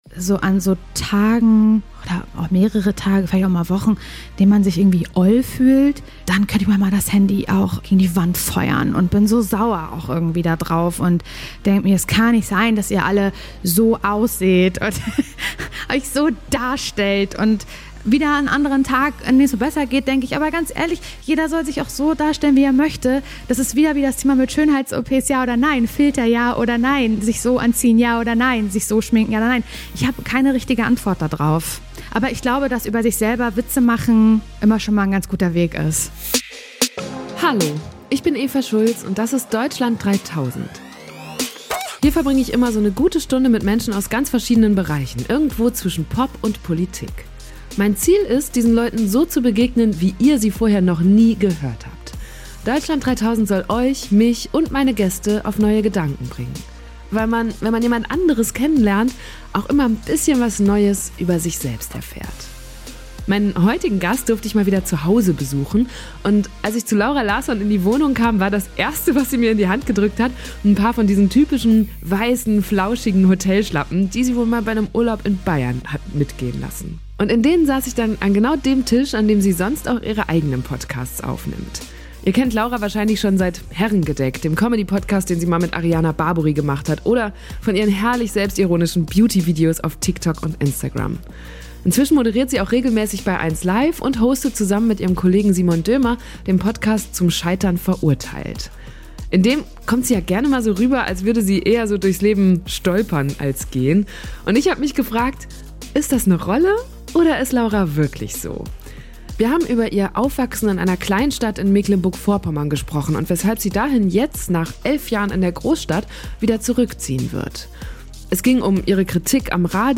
Beschreibung vor 3 Jahren Meinen heutigen Gast durfte ich mal wieder zuhause besuchen.
In denen saß ich dann an dem Tisch, an dem sie sonst auch ihre eigenen Podcasts aufnimmt.